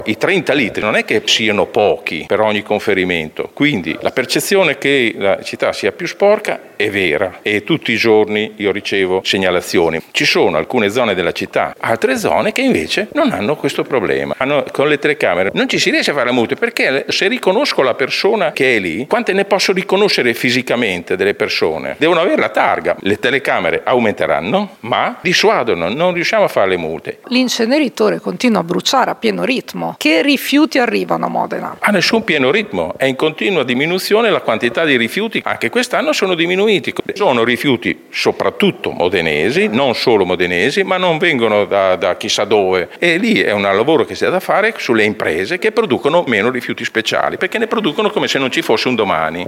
E’ una percezione” – sottolinea l’assessore all’ambiente Vittorio Molinari che abbiamo intervistato